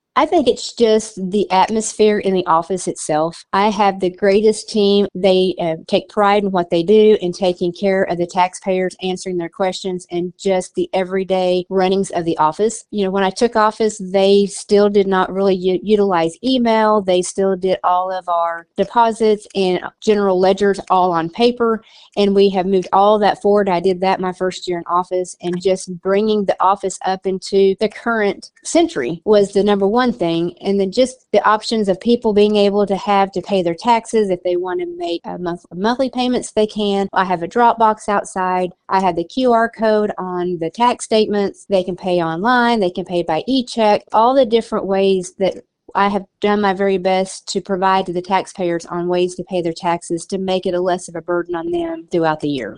When asked what she is most proud of, Smith lists some of the changes she has made to the way the office runs.